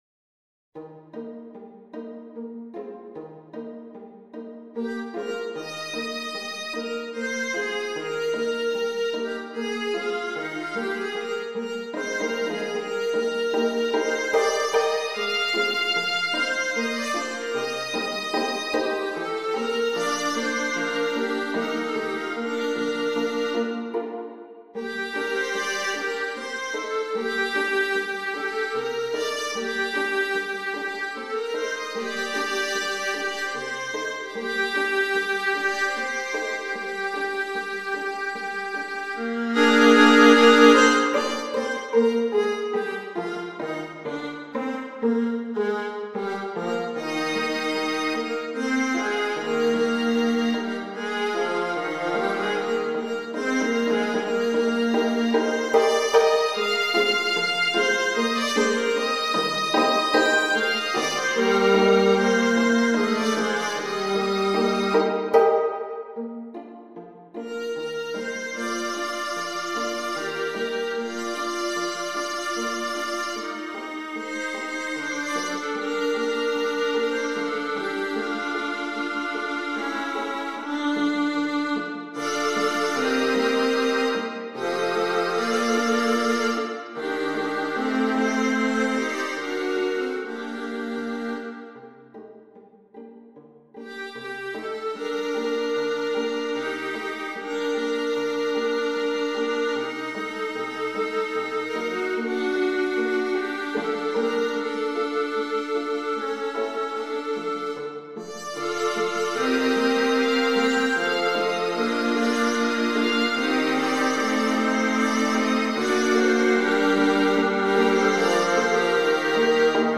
viola sextets